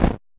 ChatBoat-Assets/Kenney/Audio/Impact Sounds/footstep_snow_004.ogg at kenney-packs
footstep_snow_004.ogg